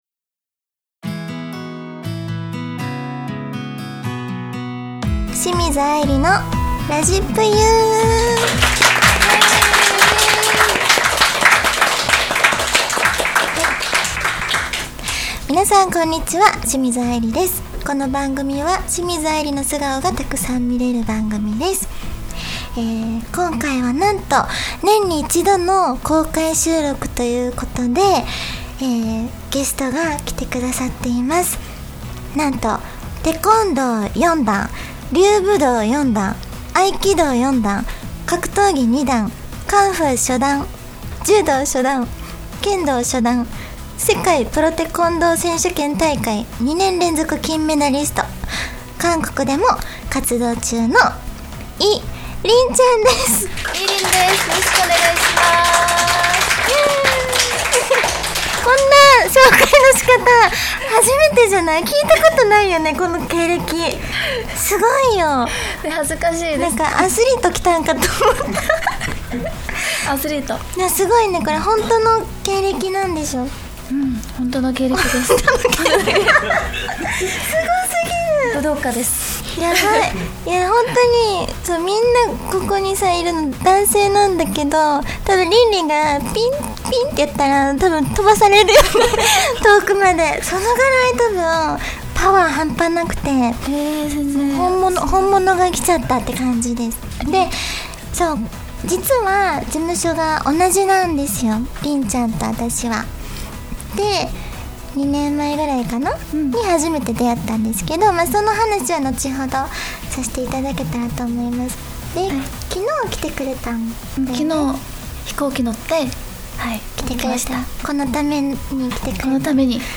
今年もこの季節がやってまいりました！年に一度の公開収録♪